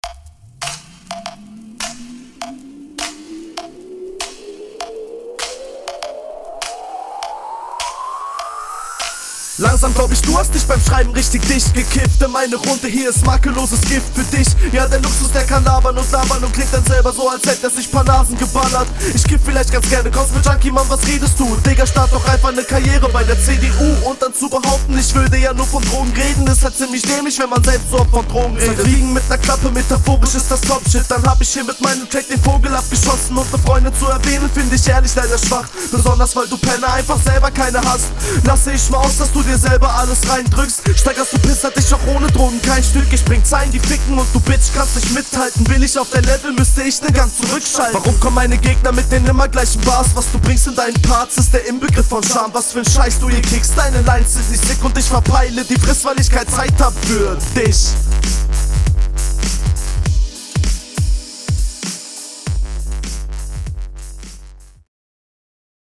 Hier meiner Meinung nach das gleiche Bild schöne Zeilen schöner Flow gehst meiner Meinung nach …